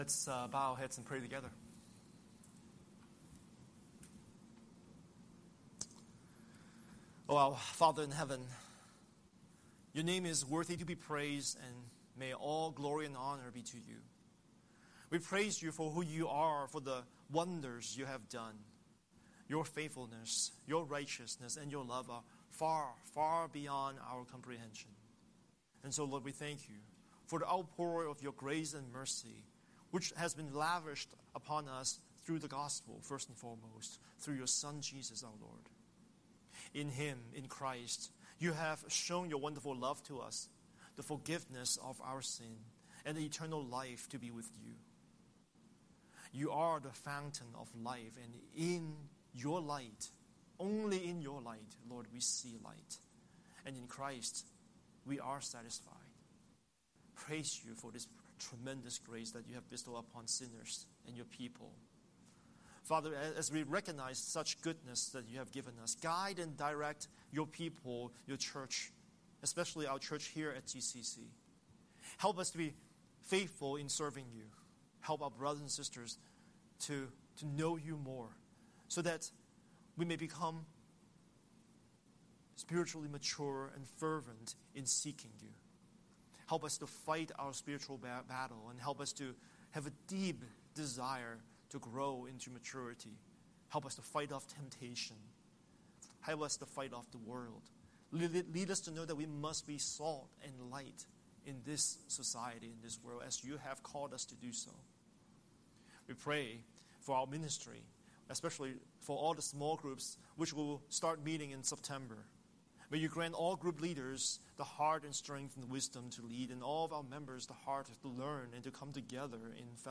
Scripture: John 11:28–44 Series: Sunday Sermon